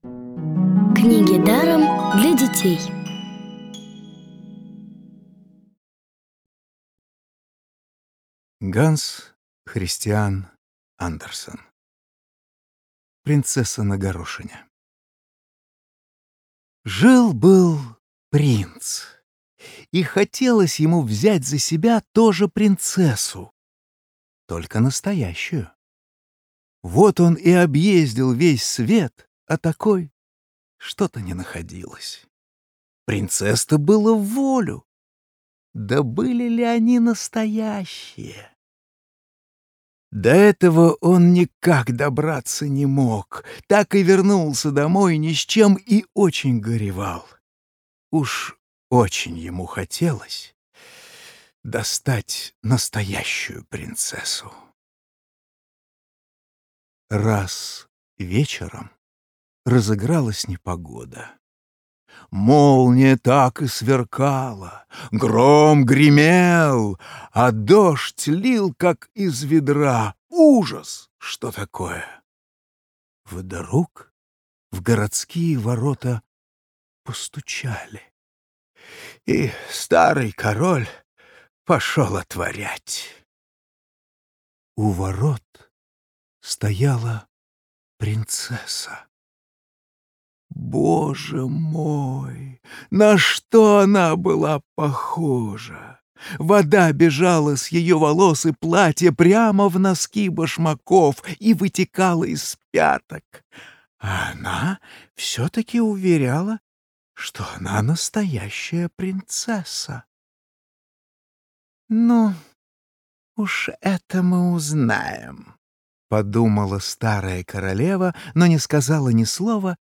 Главная Аудиокниги Для детей
Аудиокниги онлайн – слушайте «Принцессу на горошине» в профессиональной озвучке и с качественным звуком.